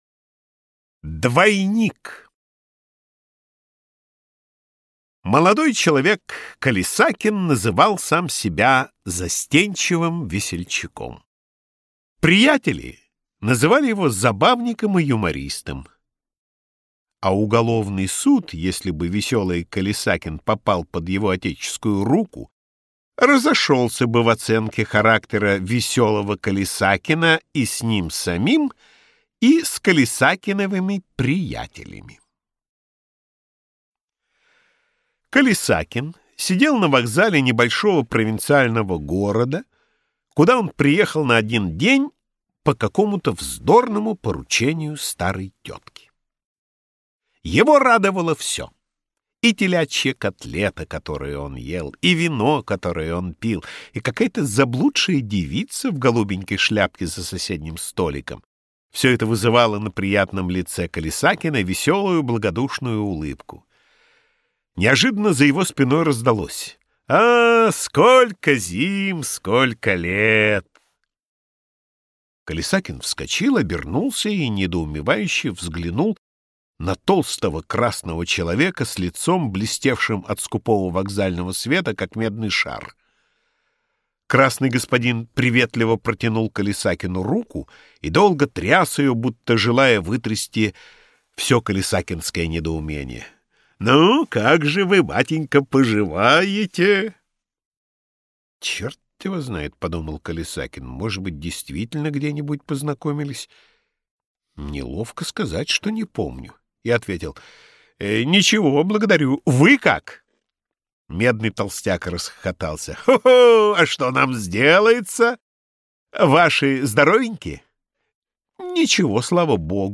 Аудиокнига Лучшие рассказы | Библиотека аудиокниг